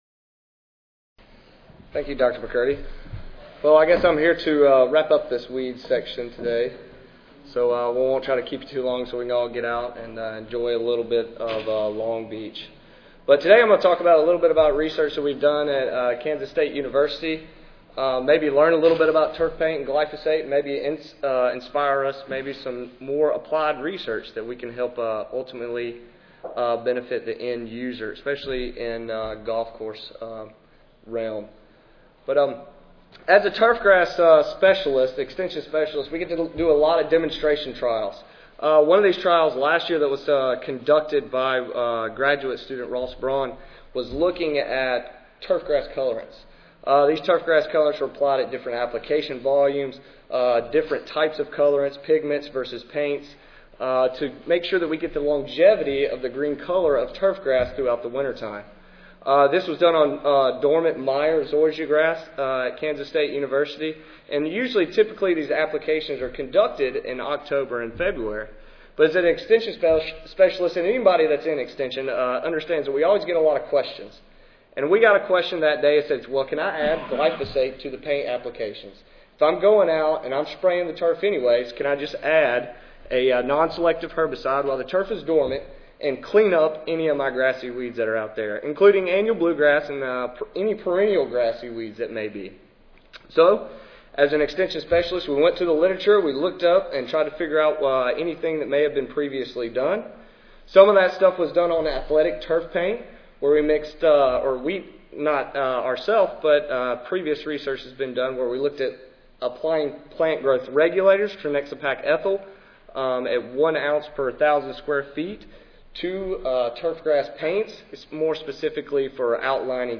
Kansas State University Audio File Recorded Presentation